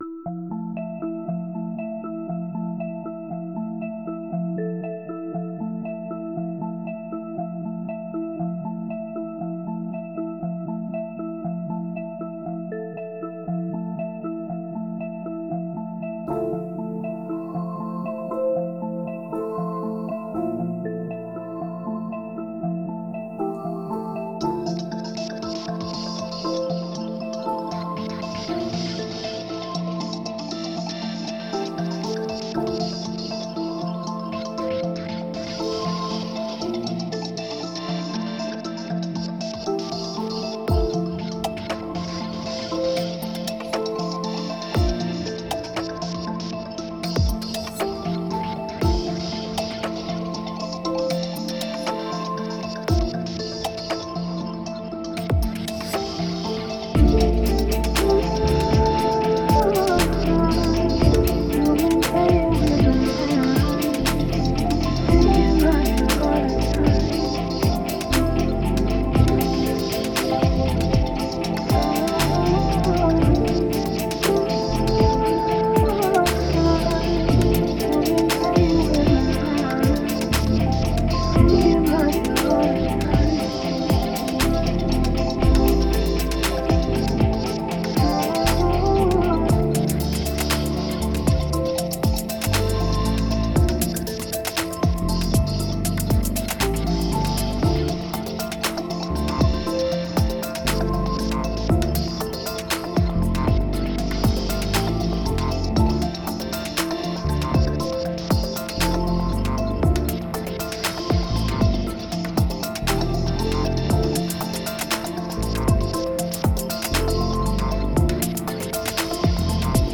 Gitarren-Chords